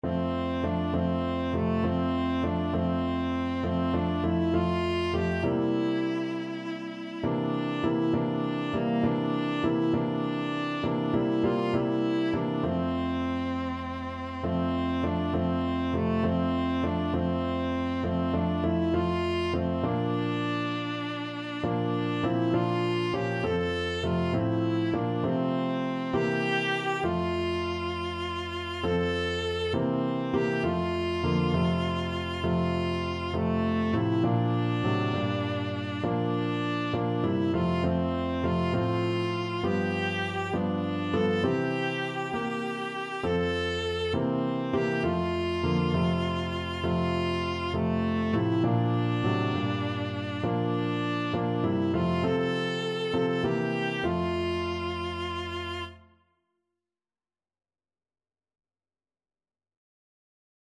6/8 (View more 6/8 Music)
Viola  (View more Easy Viola Music)
Classical (View more Classical Viola Music)